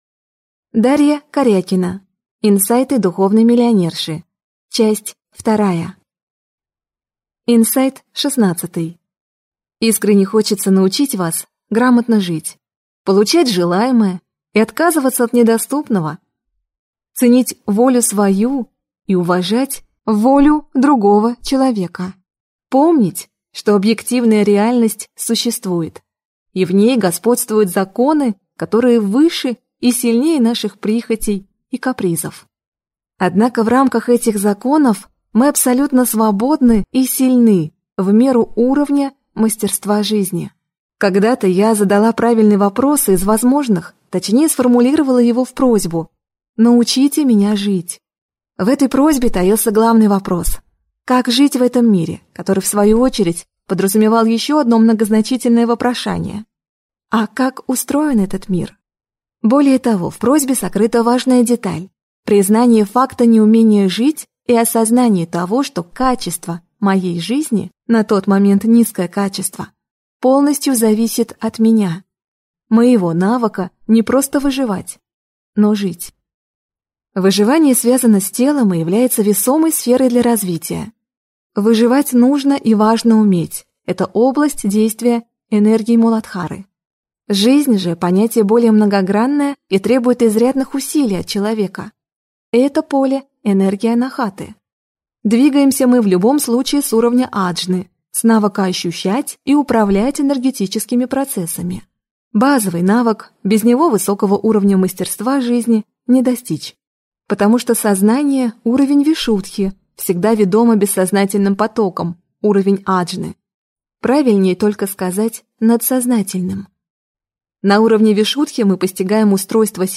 Аудиокнига Инсайты духовной миллионерши. Часть 2 | Библиотека аудиокниг